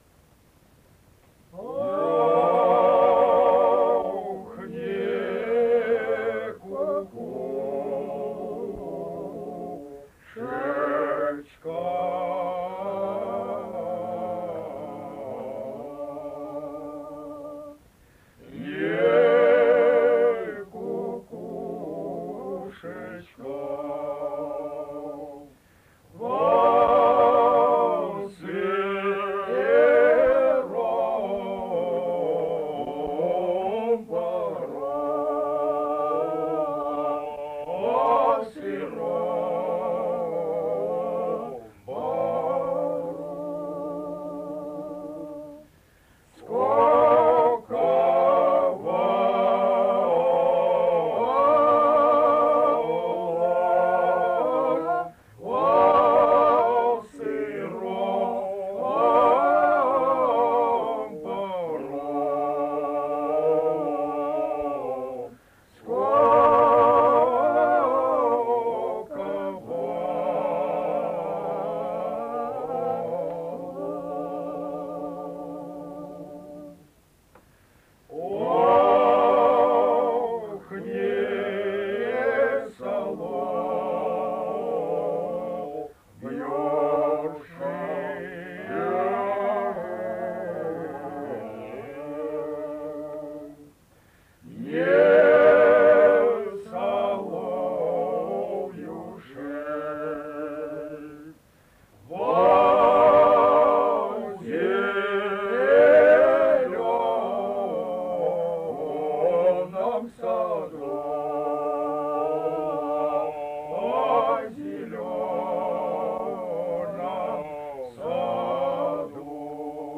Многие из этих приемов придают «плачевой» характер верхнему мужскому голосу.
Впечатляет широта мелодического объема наурских песен: напевы строятся на звукорядах, содержащих от одиннадцати до шестнадцати звуков-ступеней, тогда как диапазон русской мужской протяжной песни в большинстве своем составляет семь-двенадцать ступеней.
В аудиозаписях приведены характерные образцы местной мужской певческой традиции. «Не кукушечка во сыром бору скуковалась» — неповторимая, выдающаяся рекрутская протяжная песня.
Сложными для ее освоения являются и широкий диапазон, и взаимодействие трех голосов, и ритмика, темп, тембр, дыхание, интонация.
01 Рекрутская протяжная песня «Не кукушечка во сыром бору скуковалась» в исполнении мужского фольклорного ансамбля ст. Наурской Наурского р-на Чеченской Республики